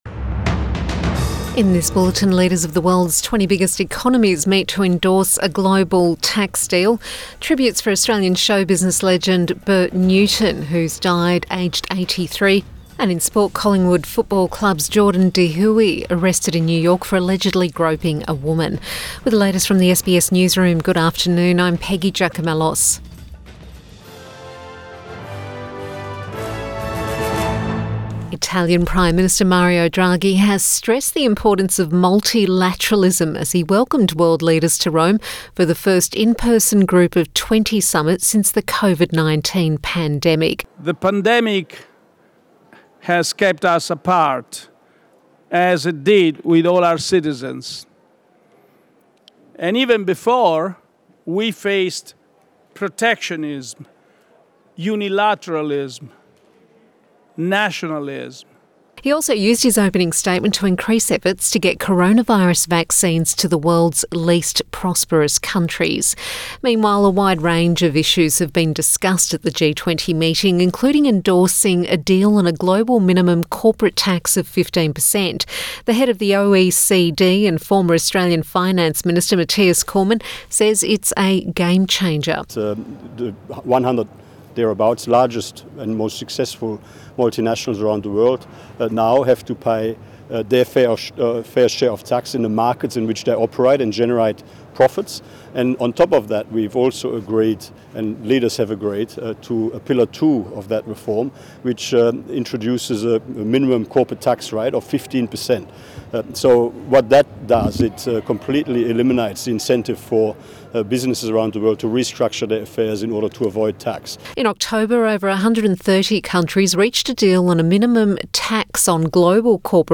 PM bulletin October 31 2021